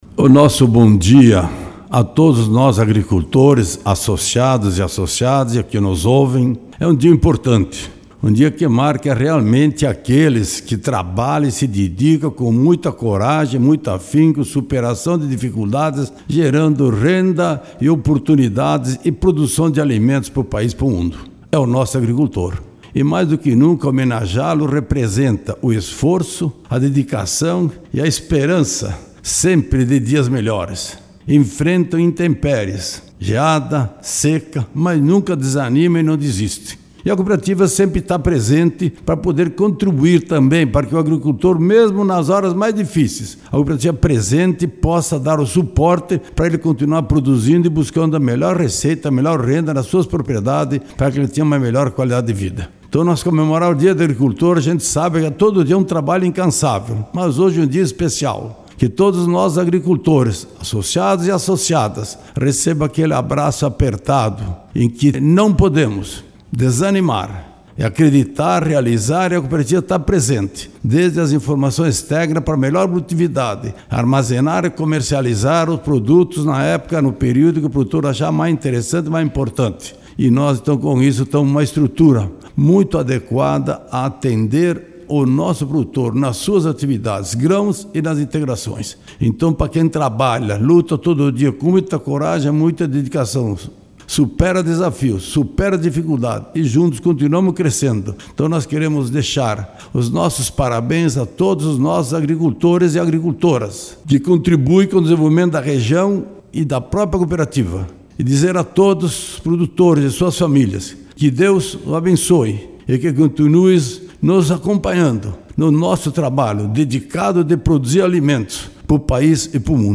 Entrevista -